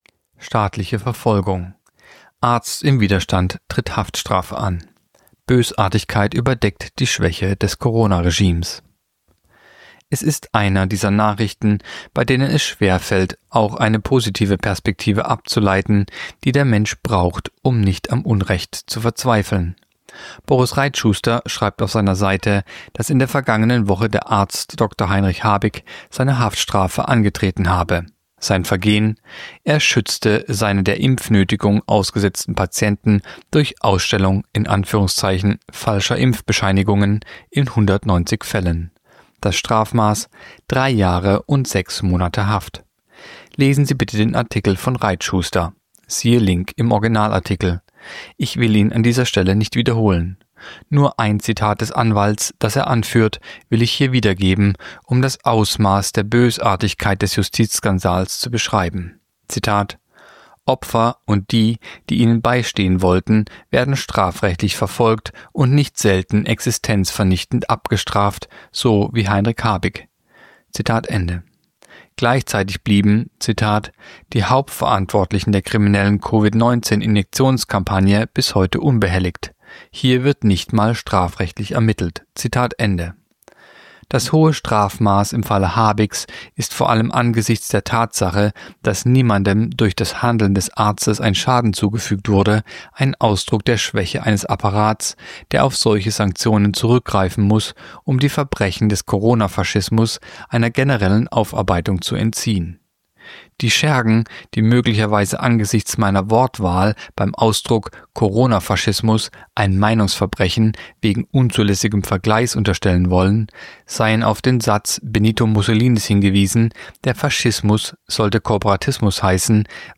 Kolumne der Woche (Radio)Arzt im Widerstand tritt Haftstrafe an